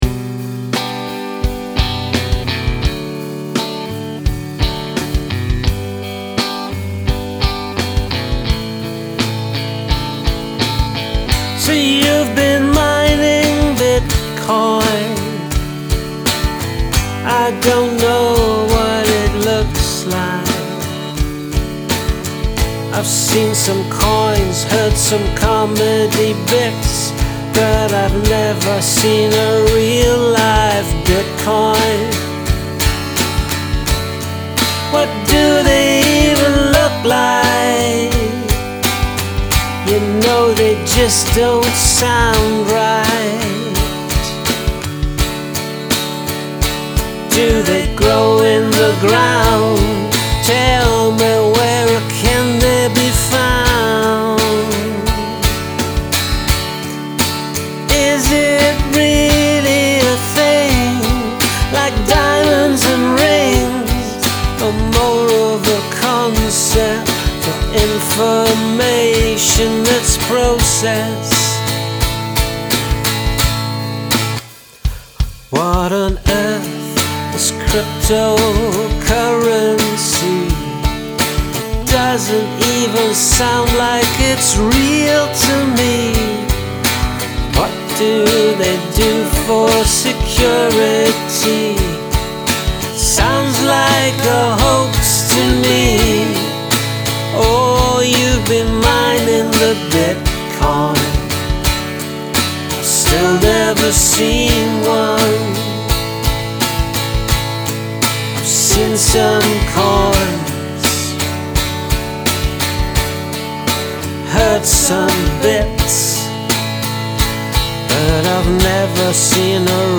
Sweet harmony vocal is on the money.
I love the harmonies.